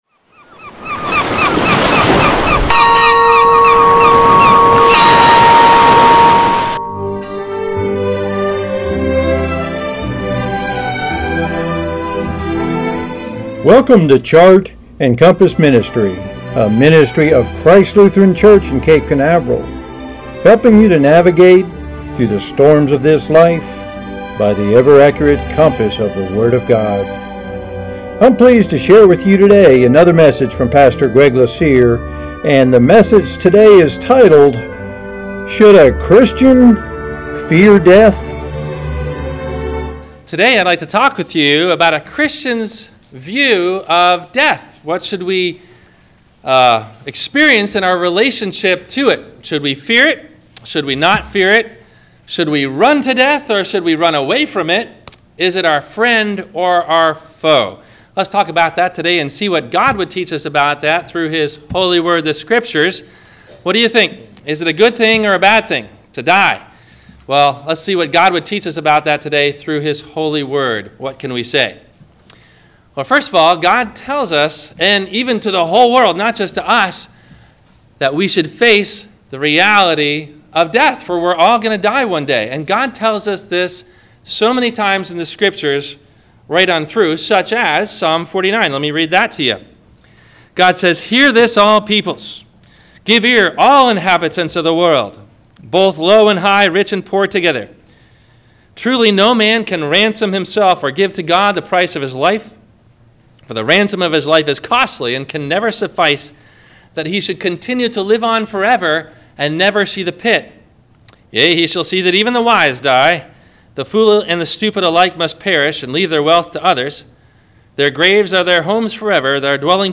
Questions asked before the Sermon message: